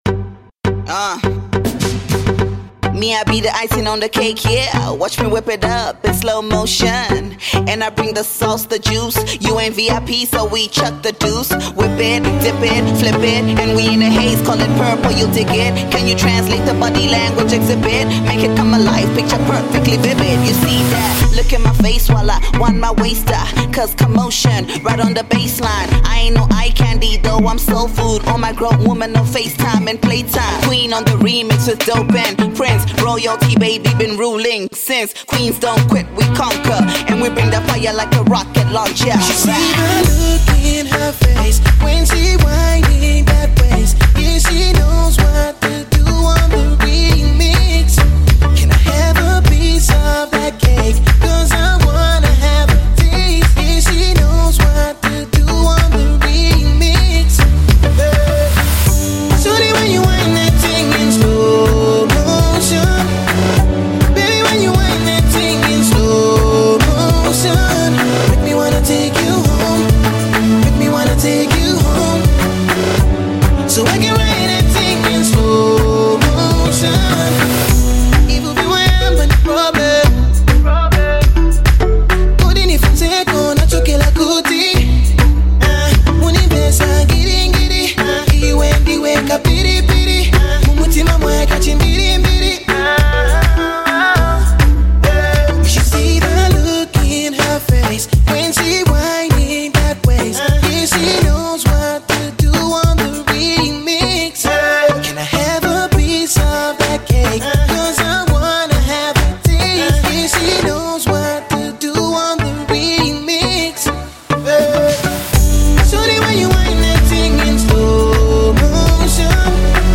Zambian urban music